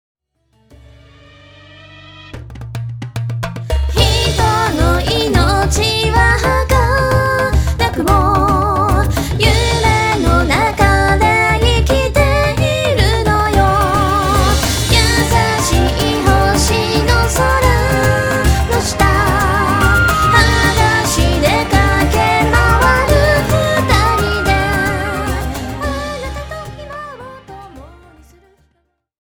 民族音楽とプログレッシヴロックの共鳴、祈りを込めた歌声、 冥府へ誘う巫女の物語。